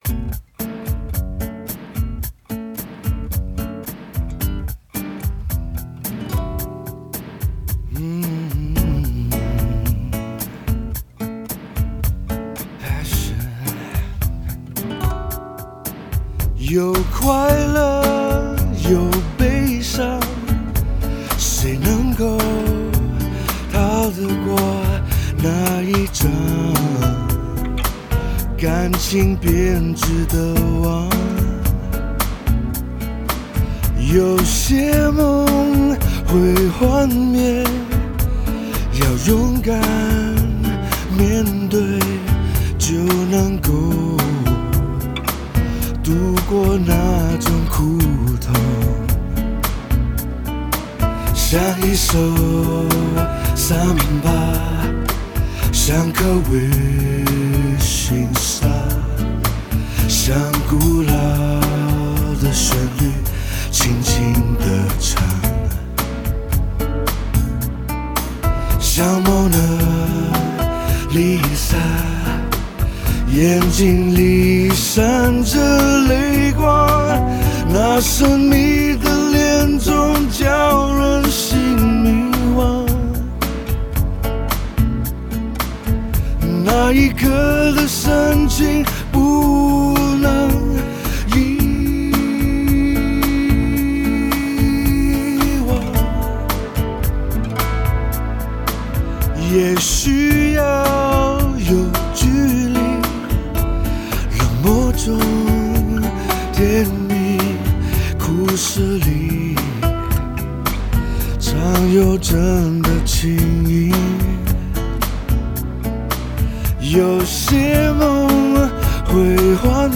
骚动城市的 真嗓音
⊙12首来自灵魂的歌声 24轨简单的录音创作